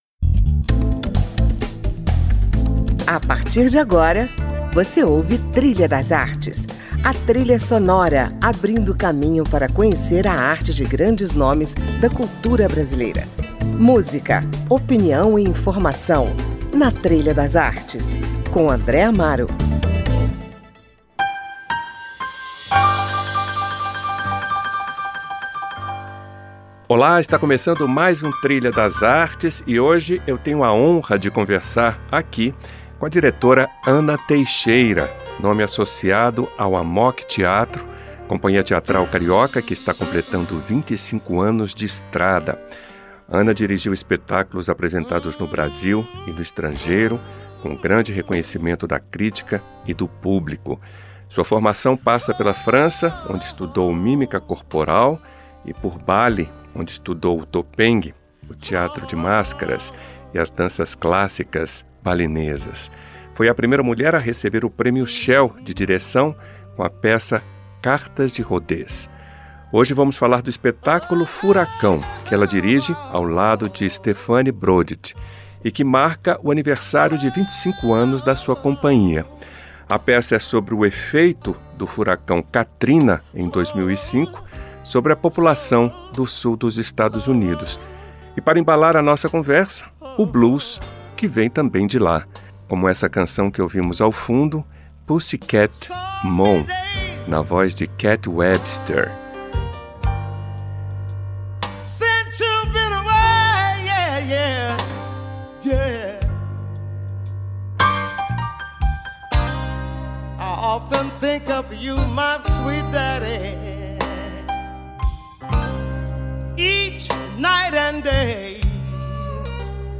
celebra 25 anos de estrada ao som de blues - Rádio Câmara